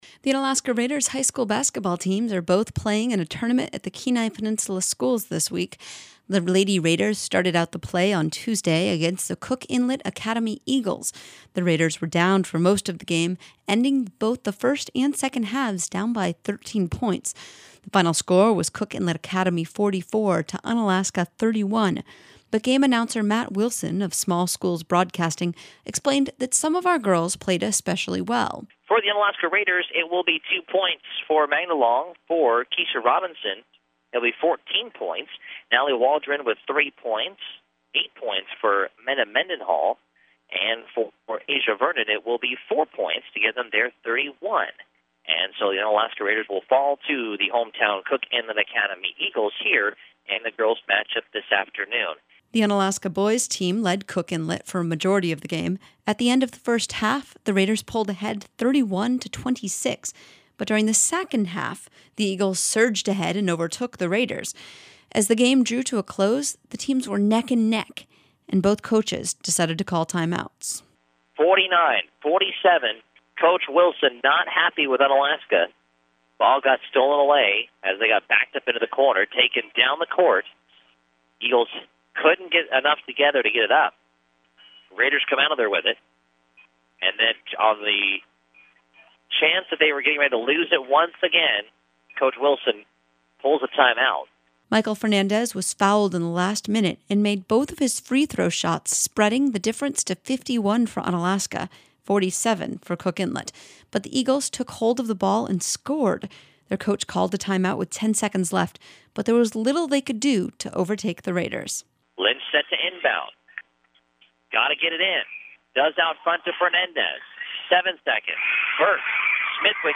The girls lost 44 to 31 and the boys won 51 to 49. Here are some highlights from the game.